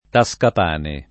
[ ta S kap # ne ]